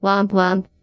WompWomp.mp3